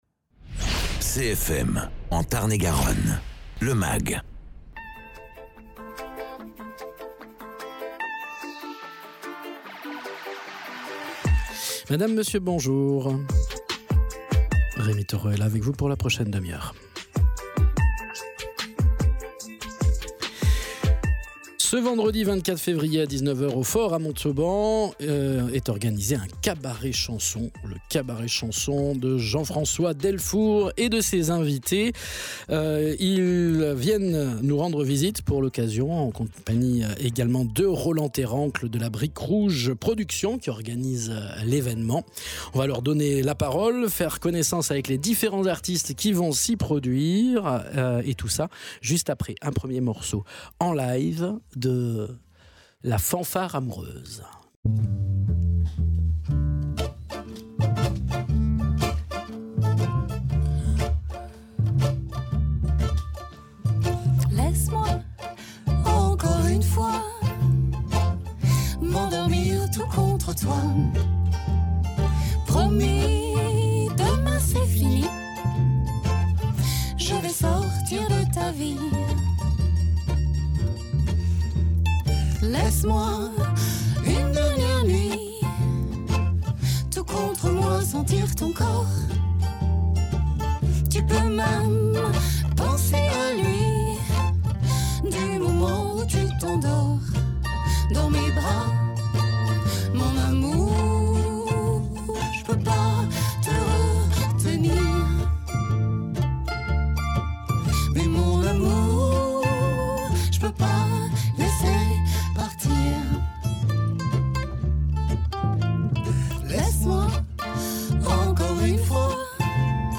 chants, guitare
contrebasse
mandoline guitare